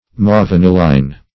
Mauvaniline \Mauv`an"i*line\, n.